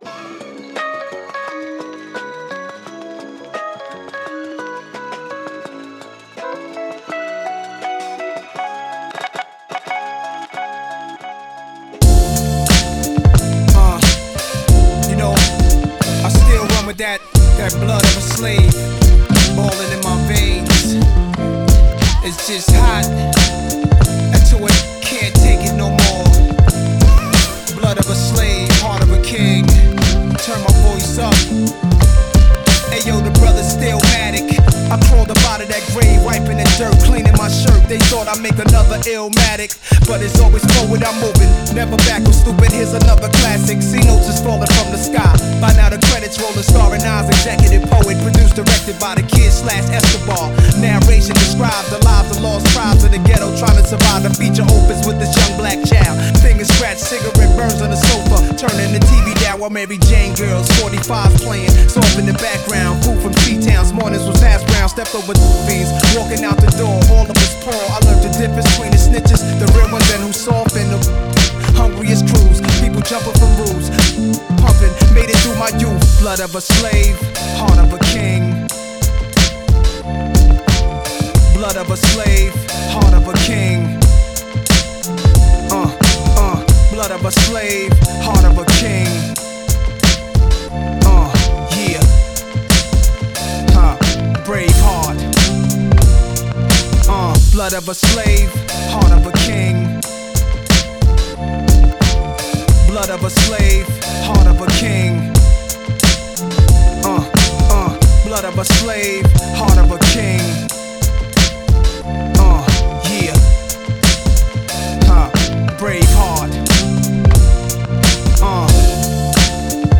tanzV3-acappella.wav